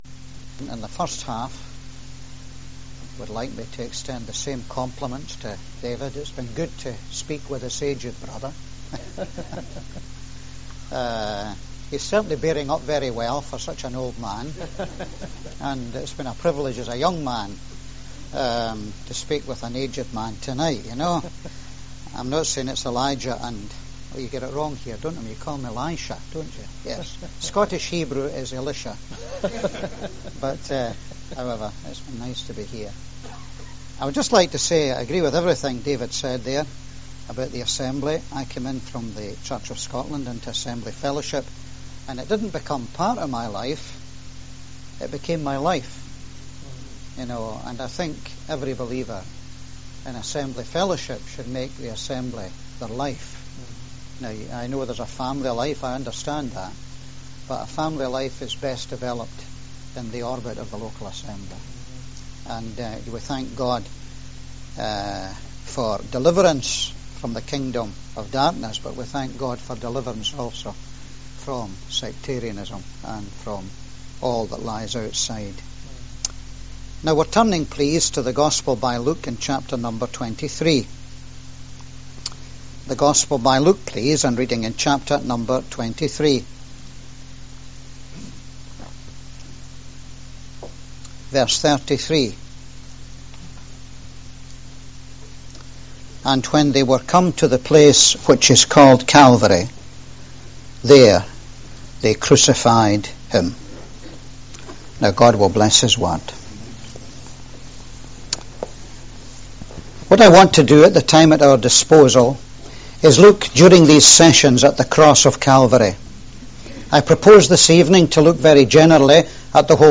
Sermons – The Gospel Hall
2003 Conference
Hebrews 10:19-25 Service Type: Ministry